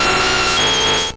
Cri de Nidoking dans Pokémon Or et Argent.